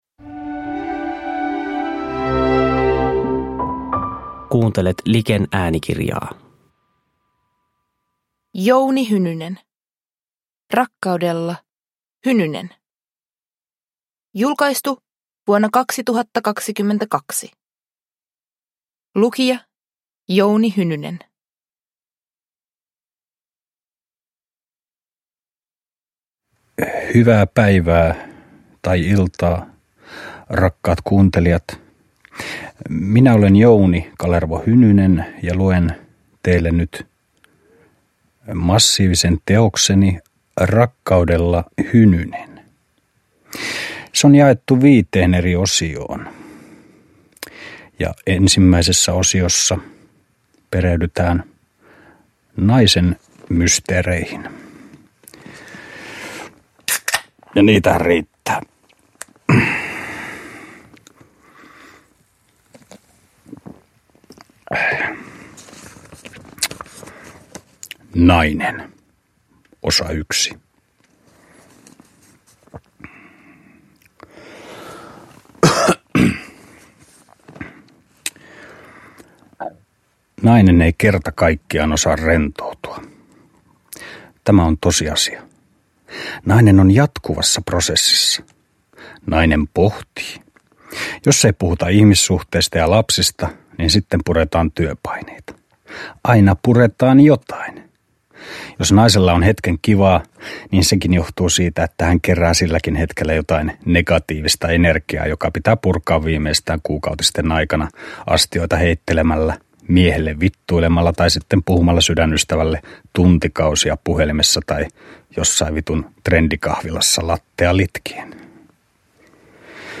Rakkaudella, Hynynen – Ljudbok – Laddas ner
Uppläsare: Jouni Hynynen